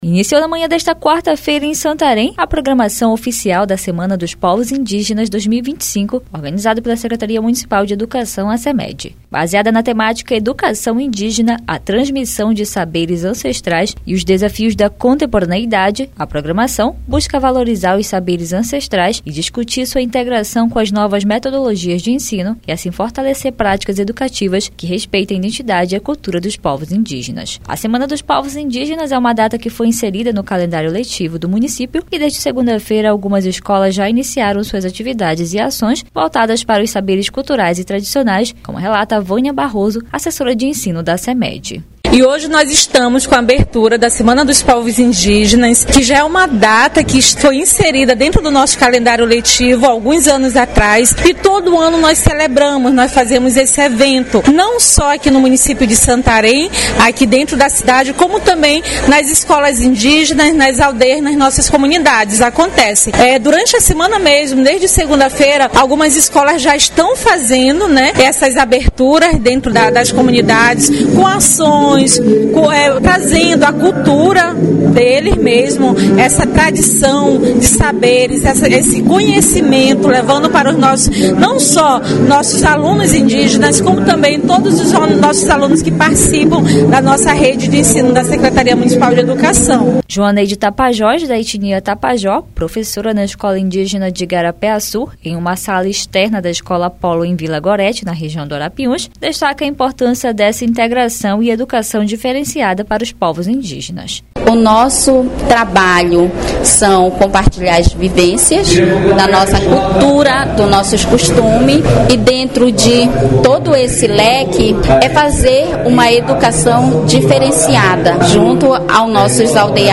Evento promovido pela SEMED integra práticas tradicionais e educação contemporânea em escolas indígenas e da rede municipal, destacando a identidade e cultura dos povos originários. A reportagem